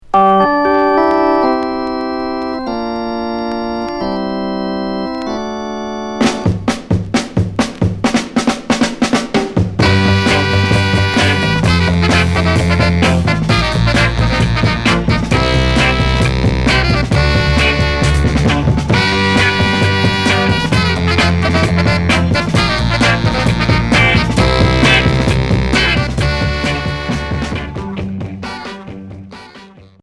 Groove beat Unique 45t retour à l'accueil